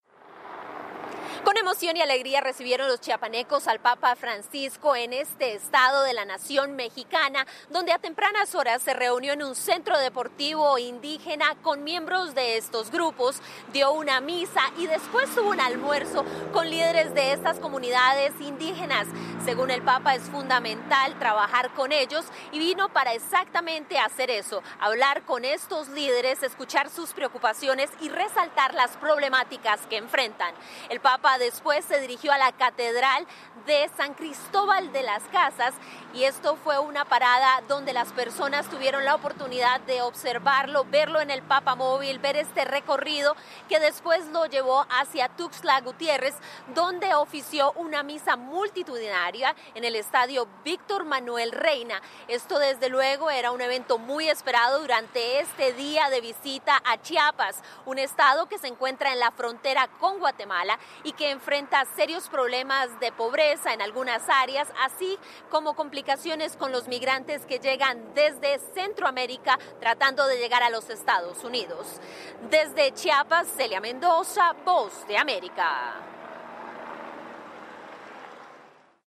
VOA: Informe desde Chiapas, visita del PAPA